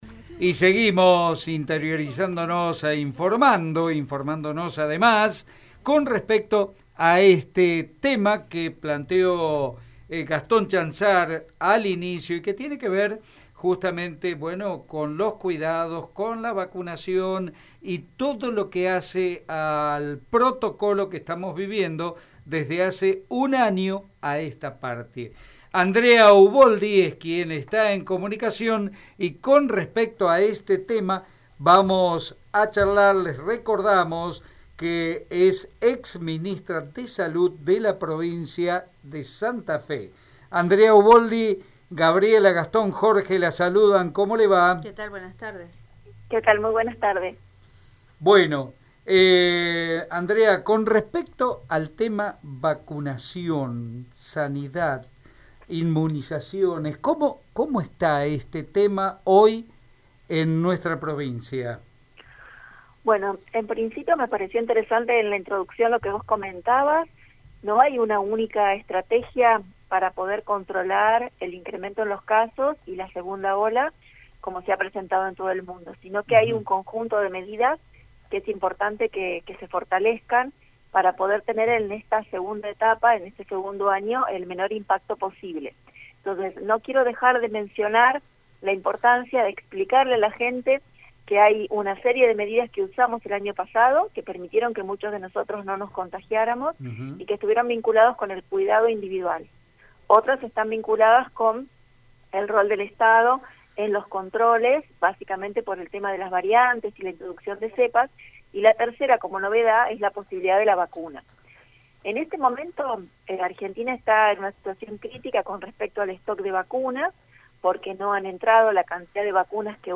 Andrea Uboldi, ex ministra de Salud de la provincia de Santa Fe e integrante del Comité de Expertos habló en Radio EME y se refirió, entre otras cosas, a cómo está atravesando el país la problemática de contagios de Covid-19.